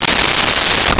Tear Paper 09
Tear Paper 09.wav